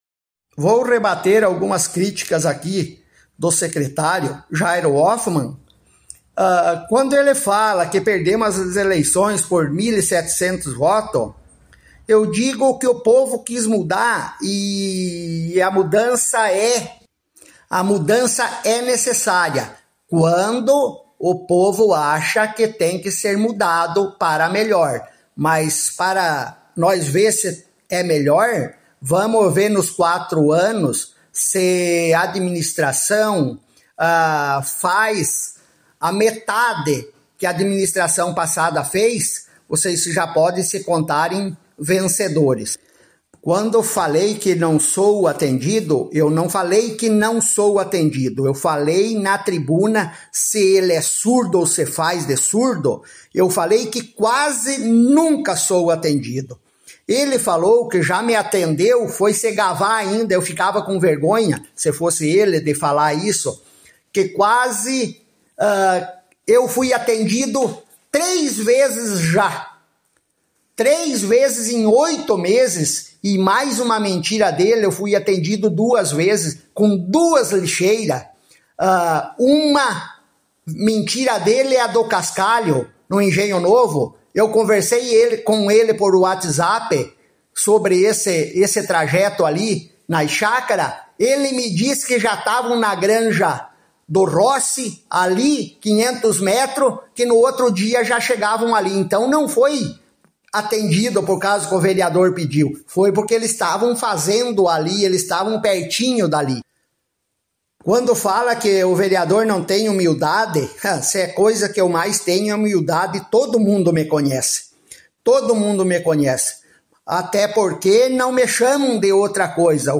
Em nova fala pública, Toaldo reforçou críticas ao secreário de Infraestrutura, Jairo Luiz Hofmann.